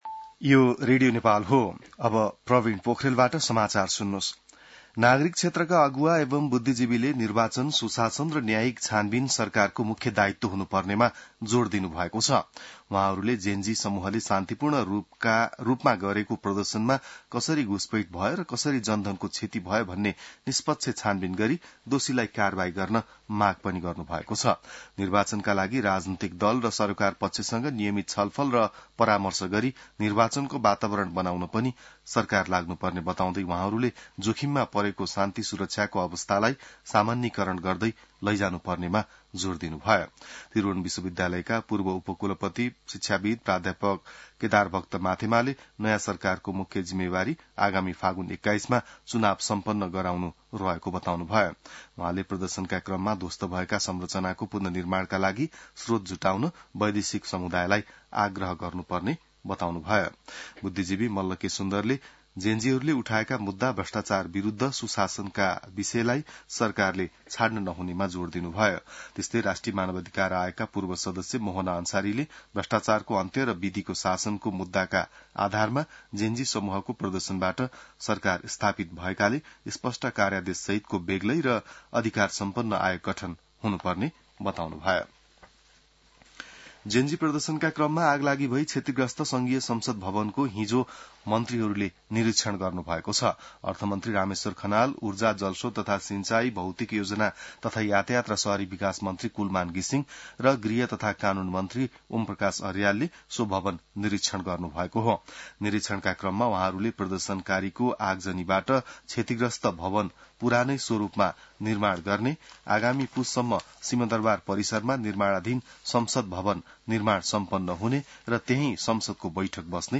An online outlet of Nepal's national radio broadcaster
बिहान ६ बजेको नेपाली समाचार : २ असोज , २०८२